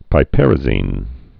(pī-pĕrə-zēn, pĭ-)